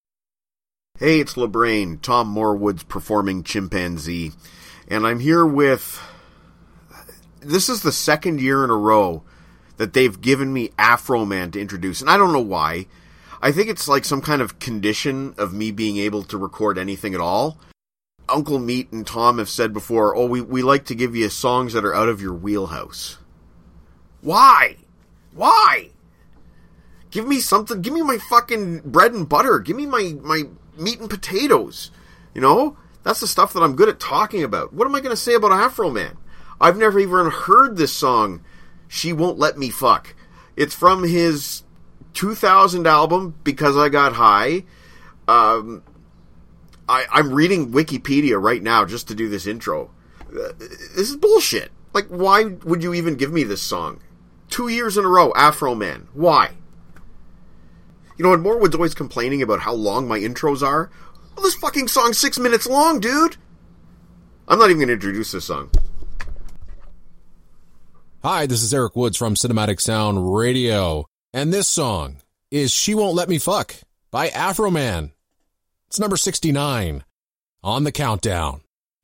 Afroman intro